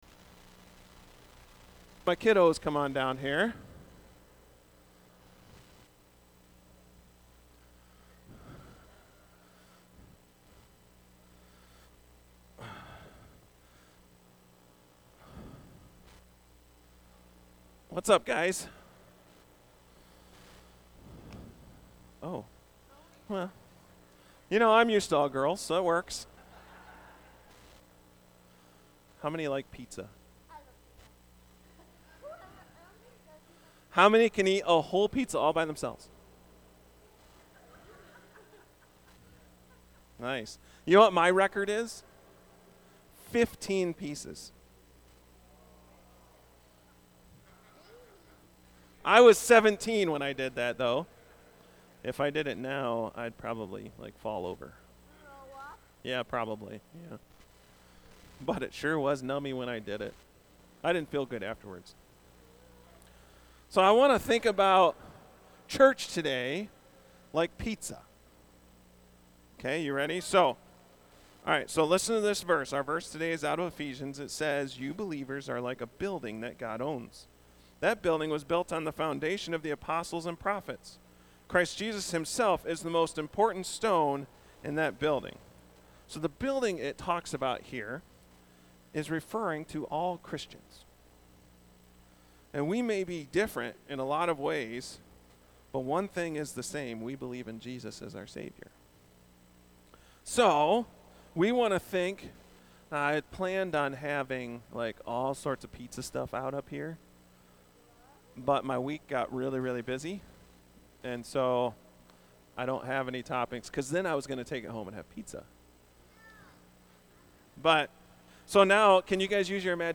Message: "Easter Service"